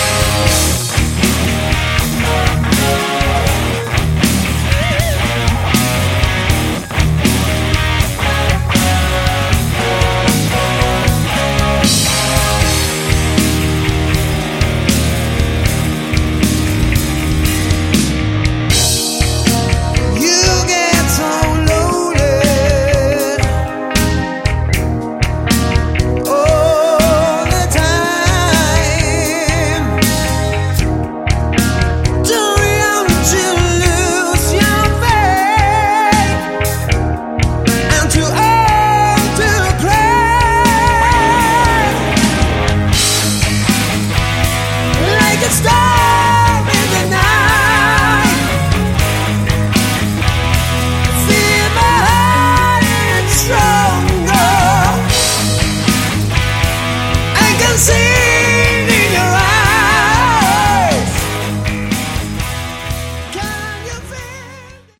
Category: Hard Rock
guitars
keyboards
drums, backing vocals